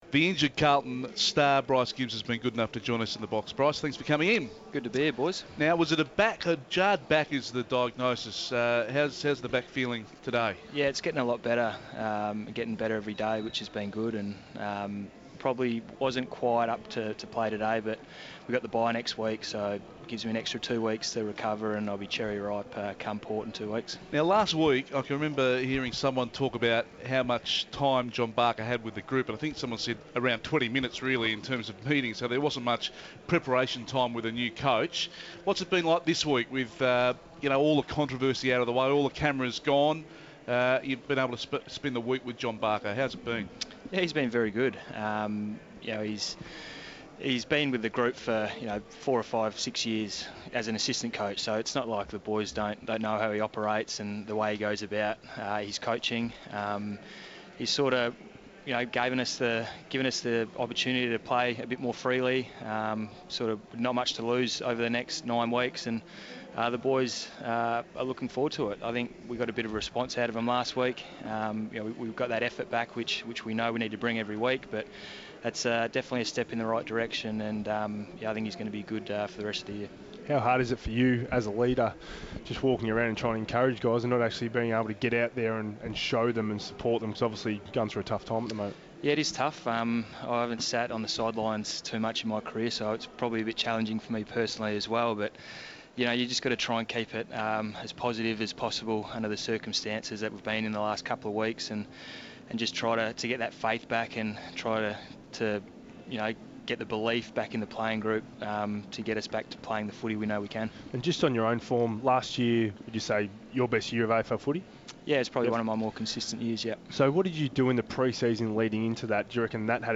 Injured Blue Bryce Gibbs joined us ahead of the Blues clash with the Crows.